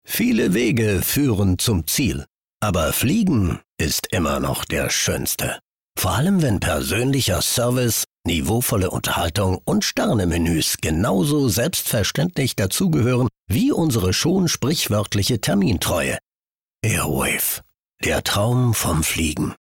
Mittel plus (35-65)
Commercial (Werbung)